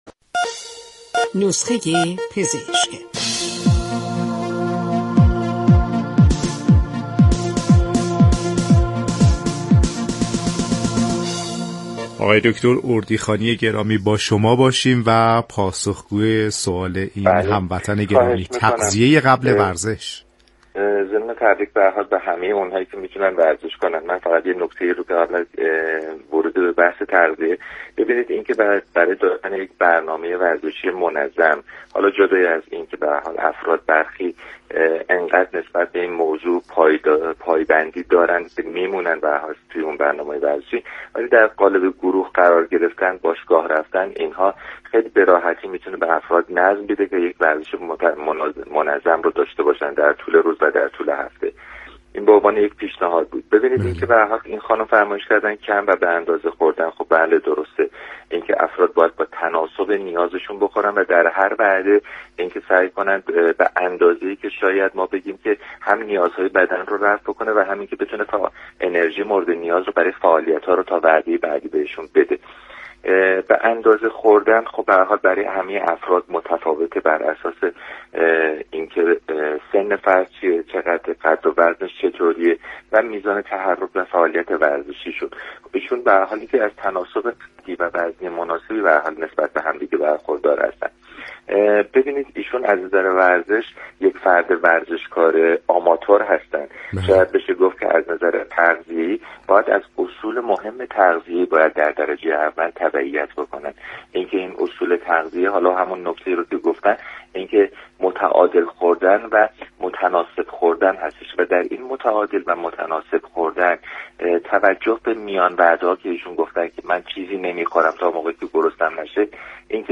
گفت وگو با برنامه نسخه ورزشی رادیو ورزش